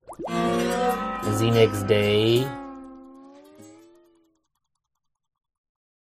spongebob-transition-the-next-day.mp3